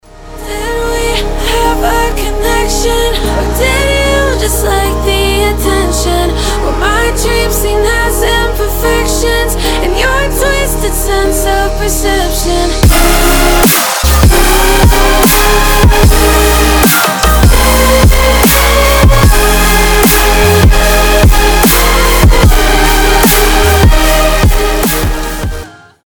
громкие
мелодичные
женский голос
Electronic